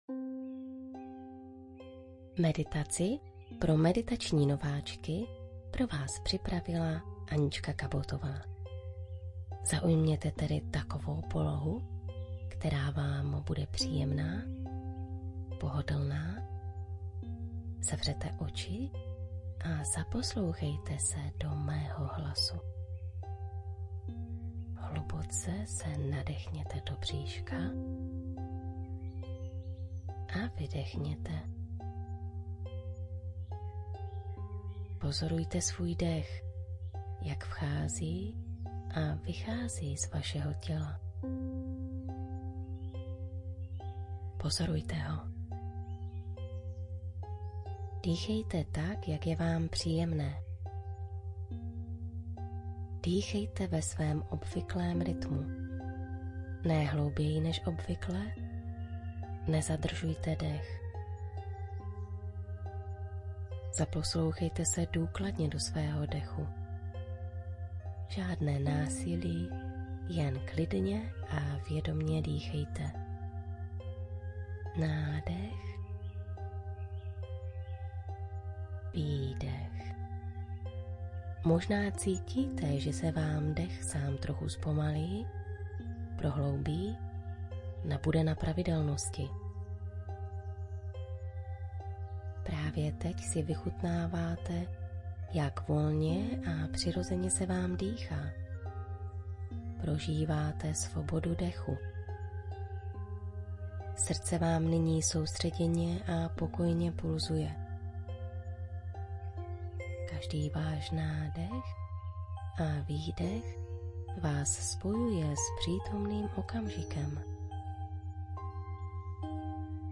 V dalších dvou máte na výběr ze dvou různých skladeb.
Meditace-pro-nováčky-3-min-hudba2.mp3